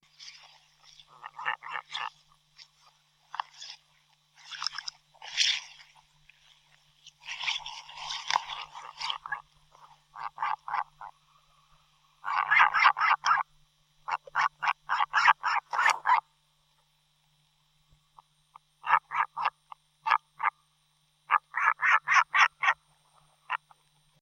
Diomedea epomophora - Albatros real del sur
albatrosreal.wav